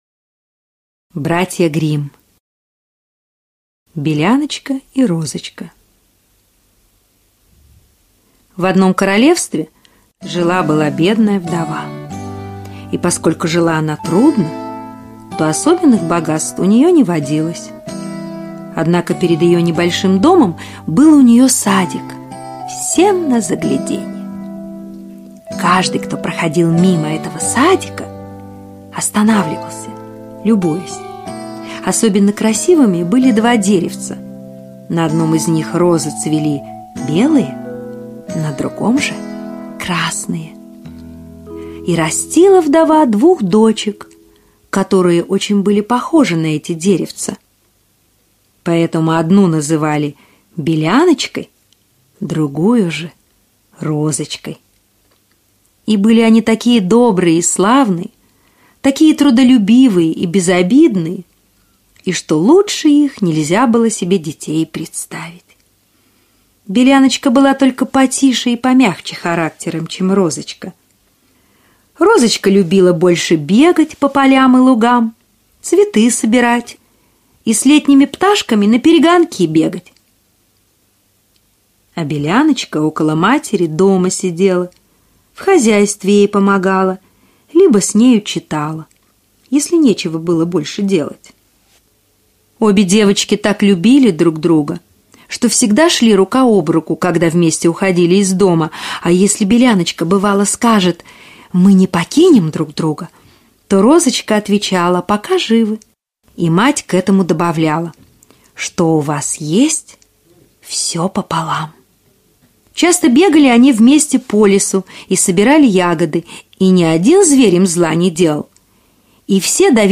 Беляночка и Розочка - аудиосказка Братьев Гримм - слушать онлайн